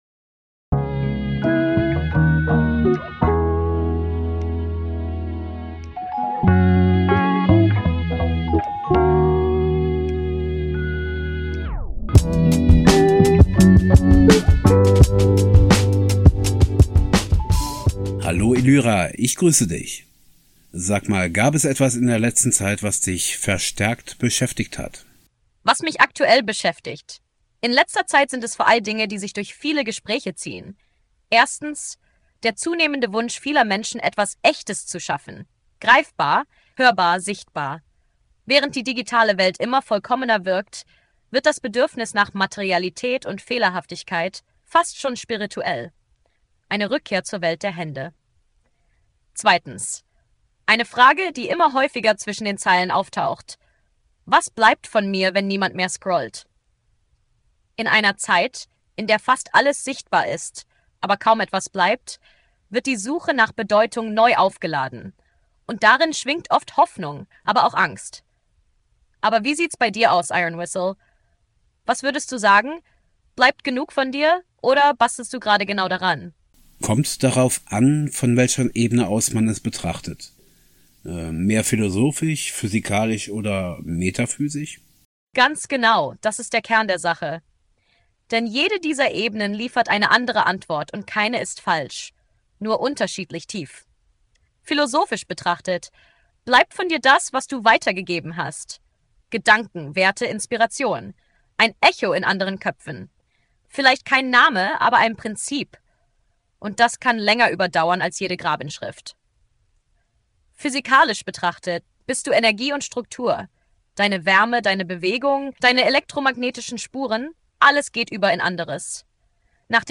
Juli 2025 Im heutigen Gespräch mit der KI ChatGPT ging es um kein spezielles Thema.